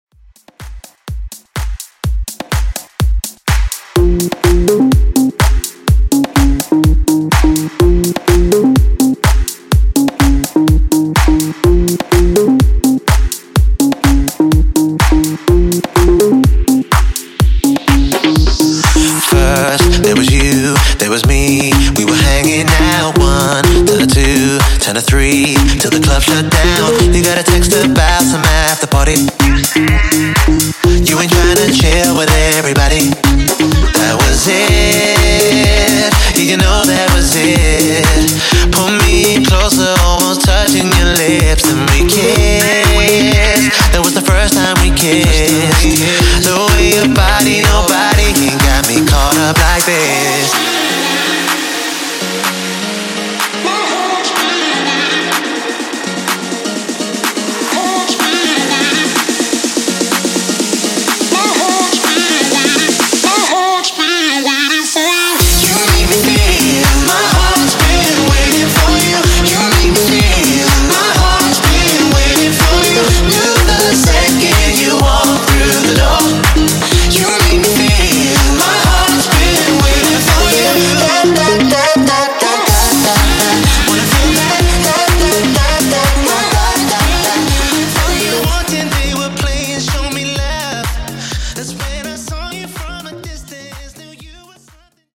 • (Audio & Video Editor) Open Format Dj
Pop Dance Club